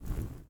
Flare03.ogg